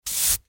Звуки подметания метлой
Звук подметания метлой осколков стекла от разбитой бутылки
Вариант 2 краткий